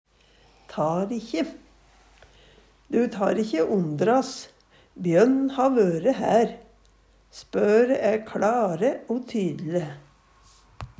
tar ikkje - Numedalsmål (en-US)
DIALEKTORD PÅ NORMERT NORSK tar ikkje treng ikkje Eksempel på bruk Du tar ikkje ondras, bjønn ha vøre hær.
Hør på dette ordet Ordklasse: Uttrykk Kategori: Kropp, helse, slekt (mennesket) Attende til søk